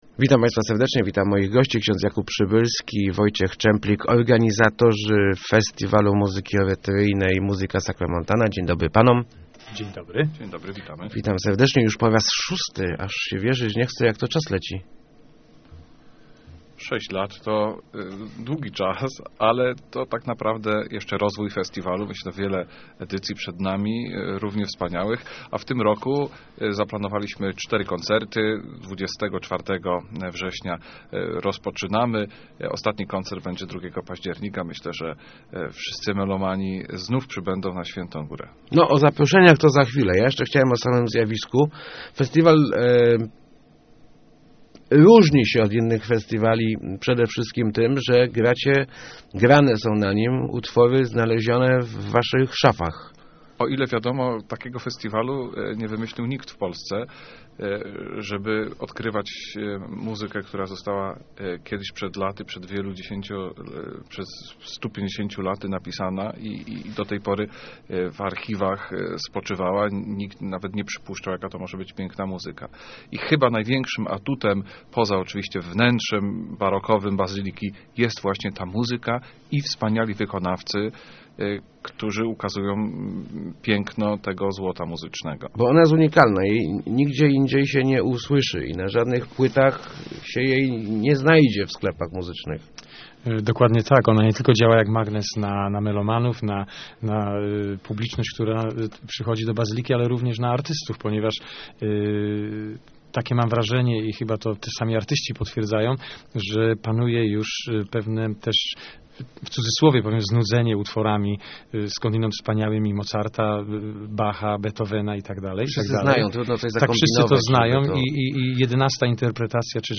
Start arrow Rozmowy Elki arrow Sacromontana po raz szósty